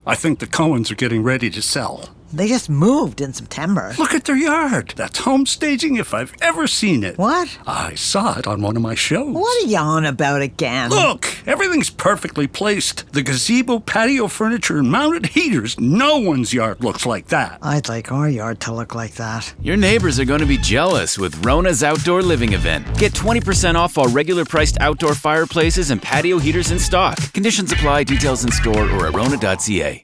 Publicité (RONA) - ANG